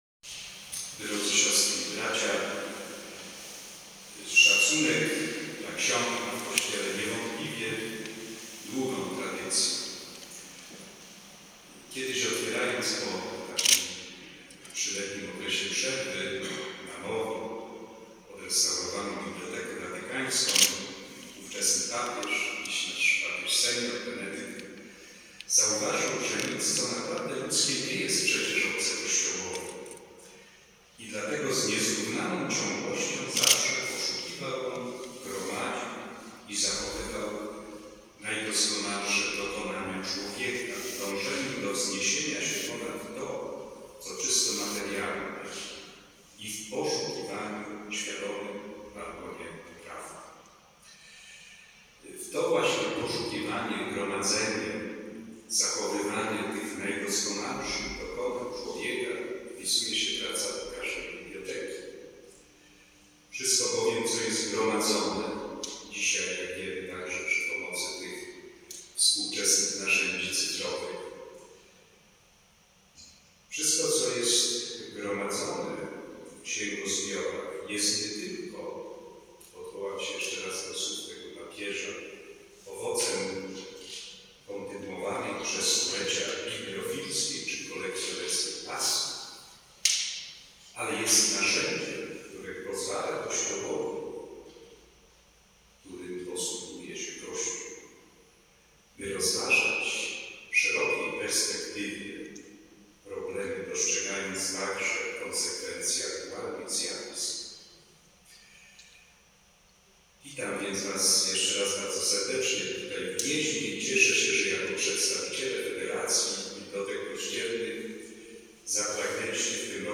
Metropolita gnieźnieński przewodniczył Mszy św. podczas odbywającego się w Gnieźnie XXVII Walnego Zgromadzenia Federacji Bibliotek Kościelnych „Fides”. W homilii Mszy św. rozpoczynającej drugi dzień spotkania nawiązał do ponad trzydziestoletniej działalności federacji podkreślając, że nie tylko przyczyniła się ona do umocnienia więzi środowiska bibliotek kościelnych w Polsce, ale nade wszystko owocnie realizowała swój podstawowy cel czyli wspomaganie ewangelizacyjnej misji Kościoła, która jest kontynuacją misji samego Jezusa Chrystusa.